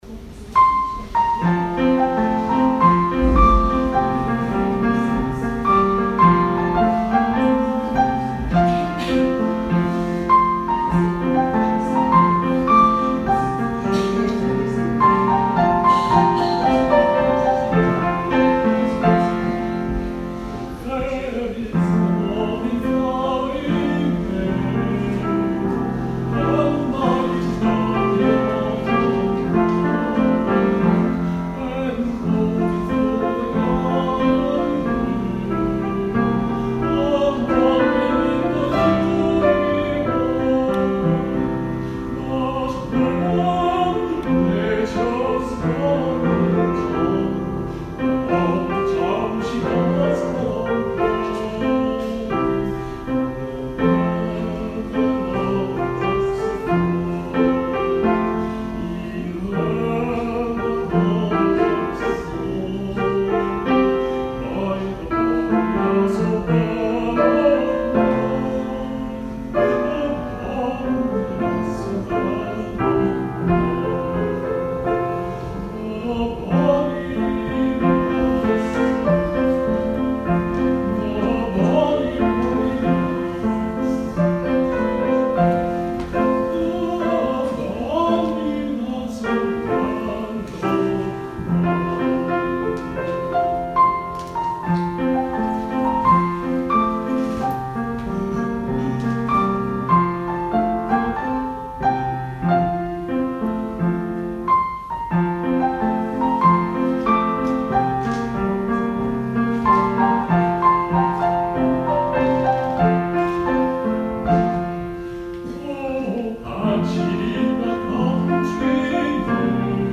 Burns Night Supper -  25 January 2019
piano